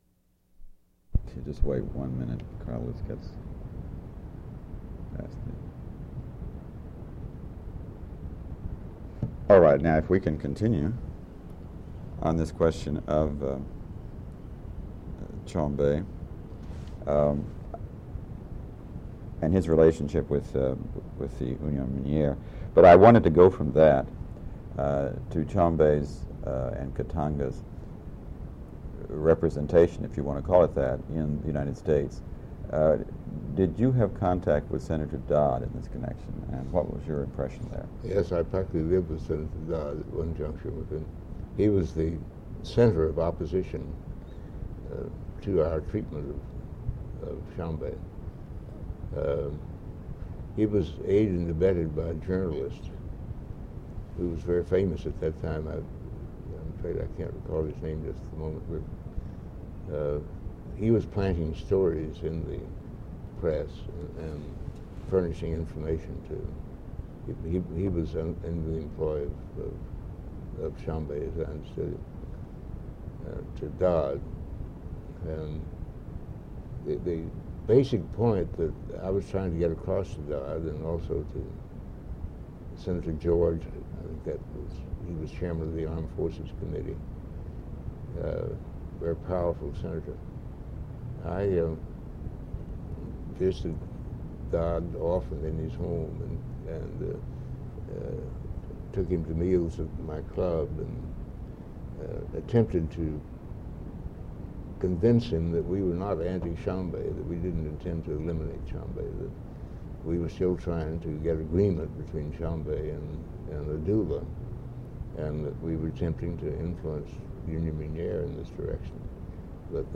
Interview with Ambassador George McGhee /